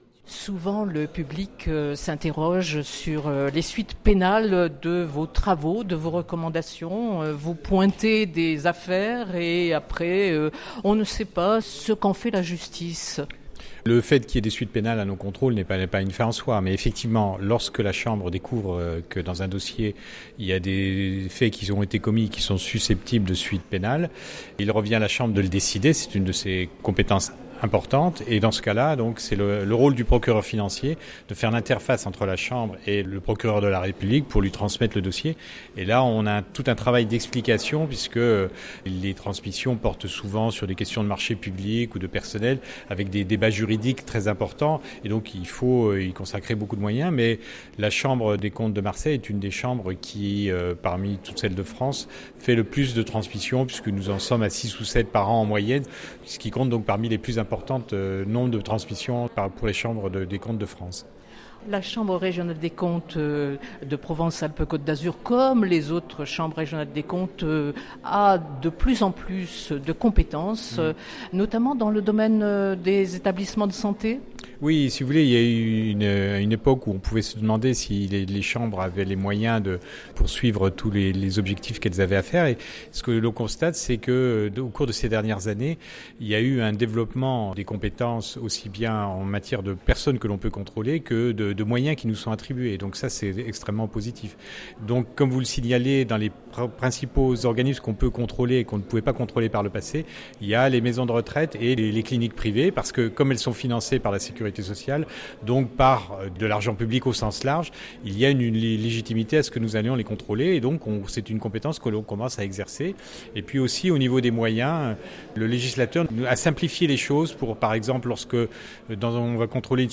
son_copie_petit-285.jpgMarc Larue, procureur financier de la CRC revient sur la transmission des dossiers au Parquet…Entretien.